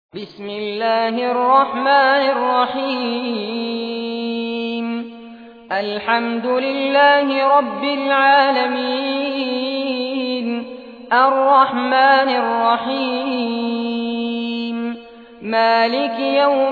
Surah Fatiha Recitation by Sheikh Fares Abbad
Surah Fatiha, listen or play online mp3 tilawat / recitation in Arabic in the beautiful voice of Sheikh Fares Abbad.
1-surah-fatiha.mp3